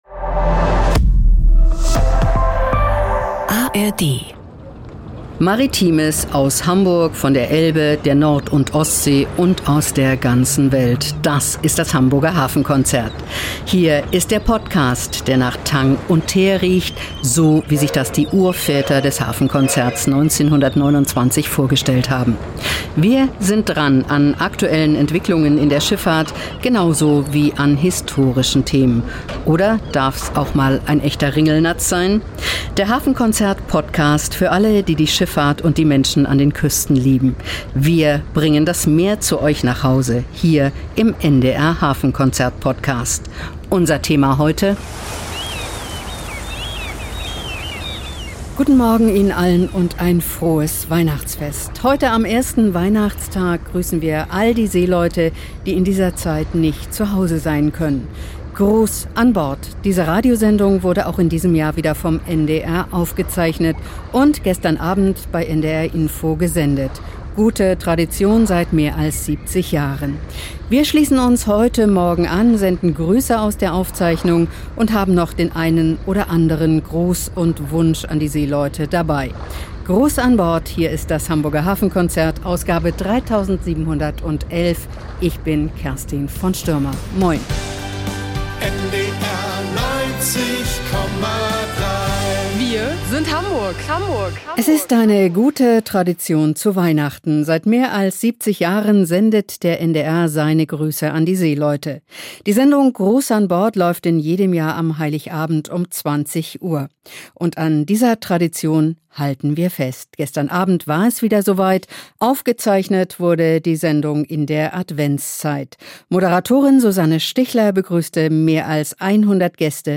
Auch in diesem Jahr sind deshalb viele Angehörige in die Seemannsmission Duckdalben gekommen, um die Weihnachtsgrüße an ihre Liebsten auf den Weg zu bringen. Extra für diese Sendung ist auch die Kurzwelle wieder aktiviert worden.